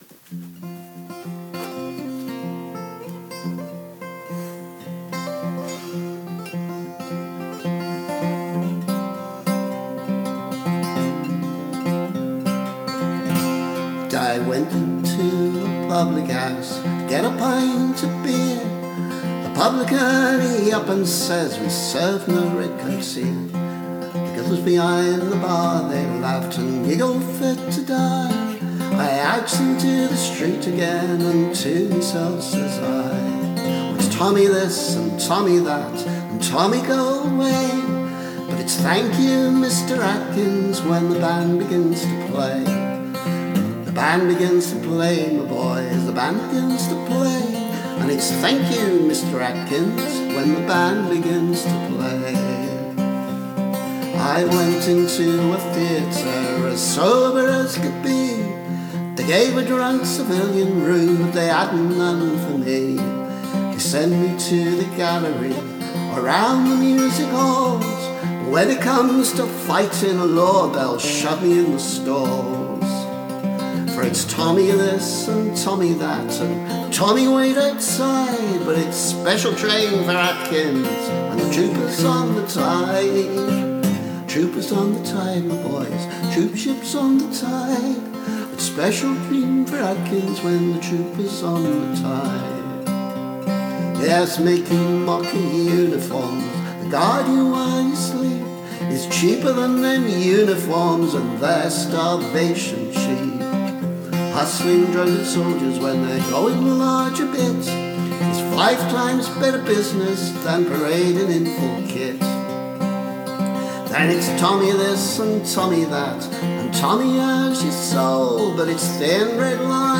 Remastered:
Actually a very rough demo, as I was in ‘make-it-up-as-you-go-along’ mode.